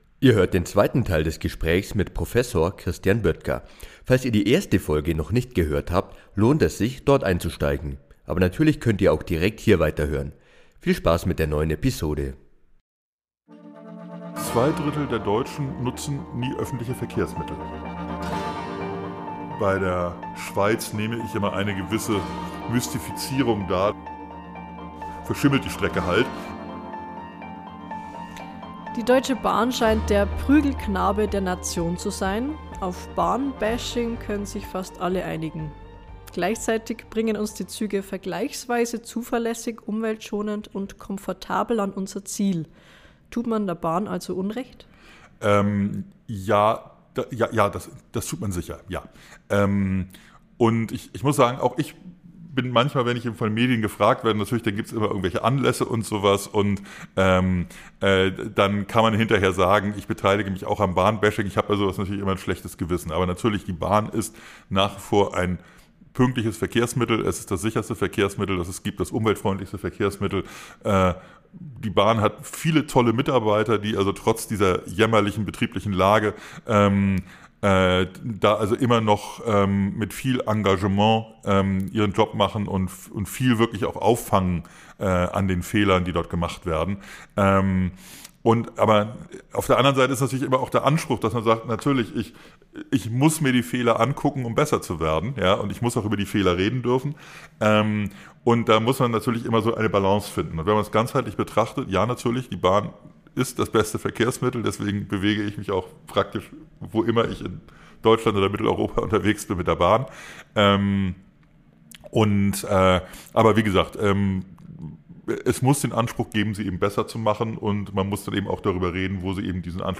Beschreibung vor 1 Jahr Über Jahrzehnte wurde zu wenig in Deutschlands Infrastruktur investiert. Nicht nur in die Eisenbahninfrastruktur, aber eben auch in jene. Im zweiten Teil des Gesprächs